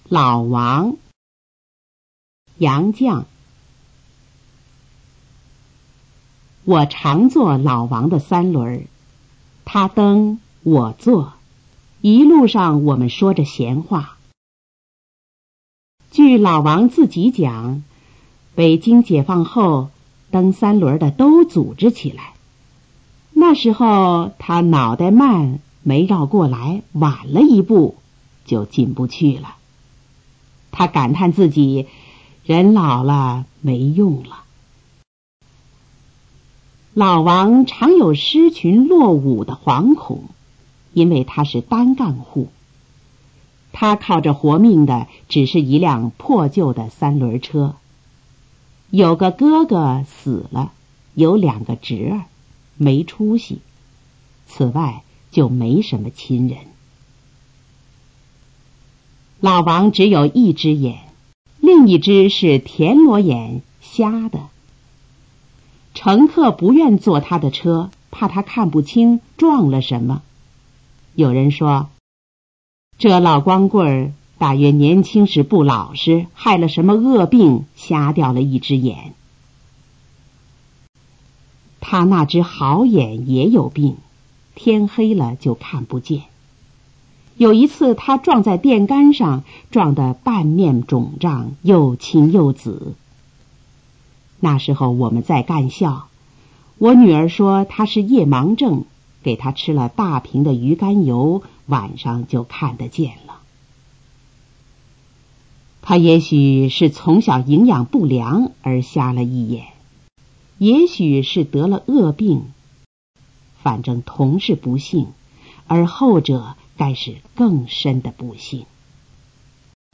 《老王》音频朗读 ☆☆☆ 点击下载资料 ☆☆☆